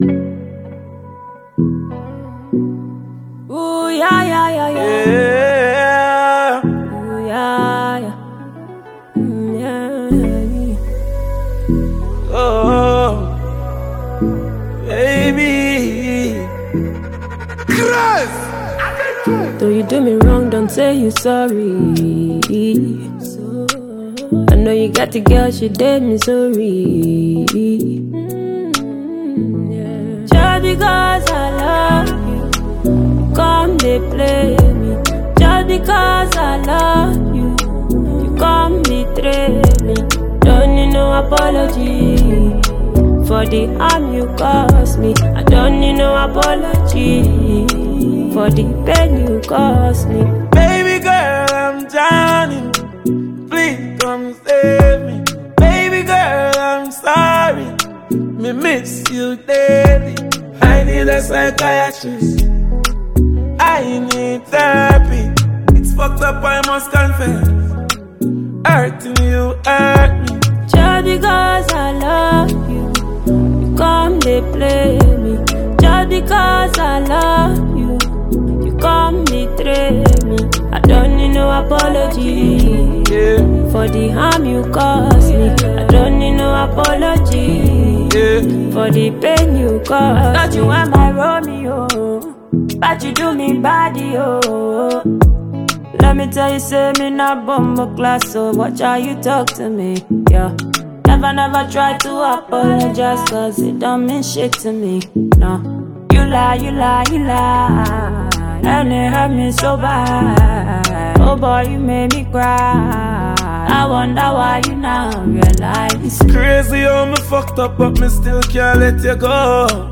Award-winning Ghanaian songstress
Jamaican dancehall sensation
Afro-Dancehall anthem